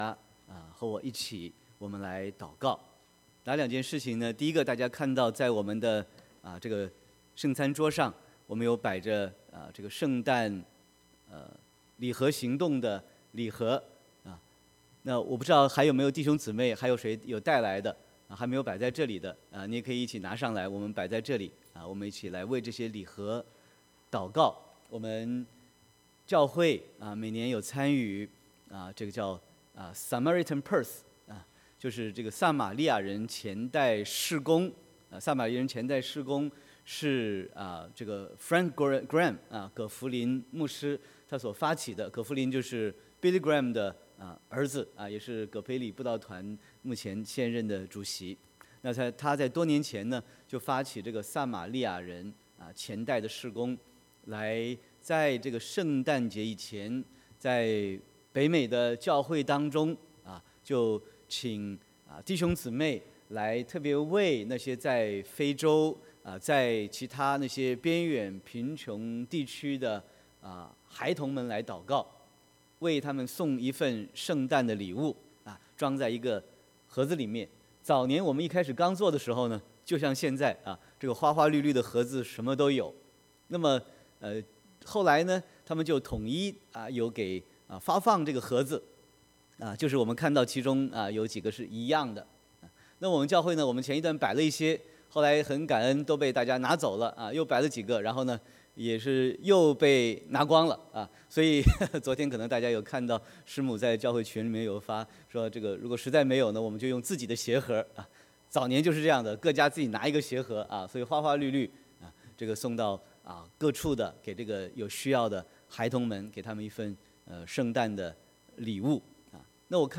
Sermon 11/18/18